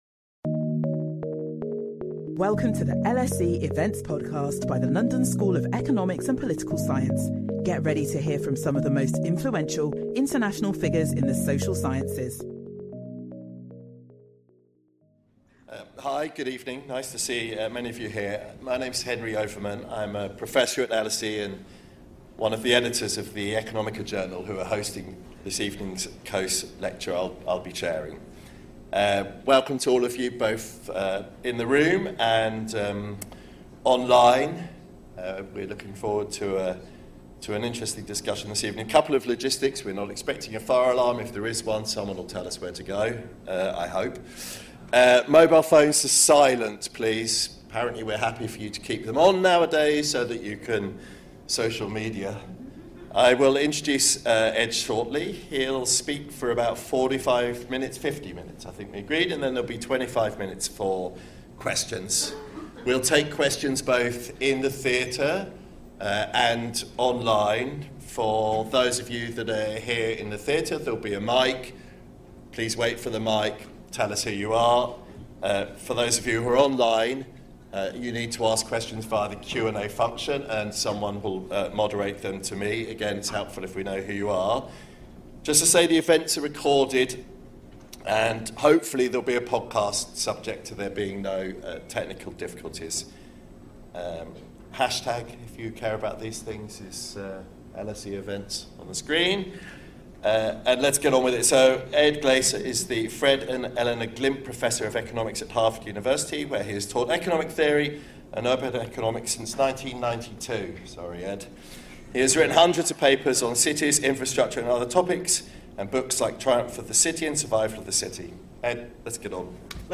Join us for this special Economica Coase lecture which this year will be delivered by Harvard academic Edward Glaeser.